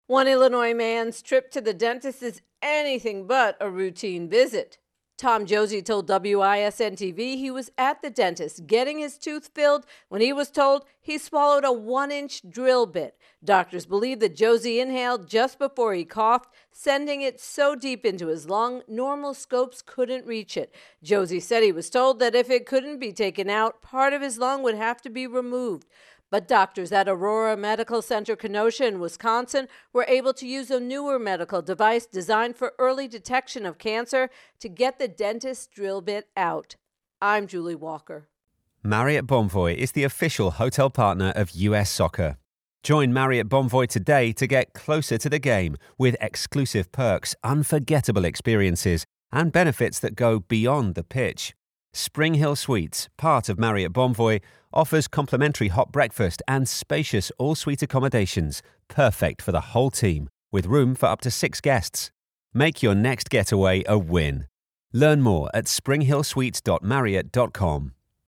ODD Inhaled Drill Bit intro and voicer